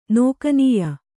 ♪ nōkanīya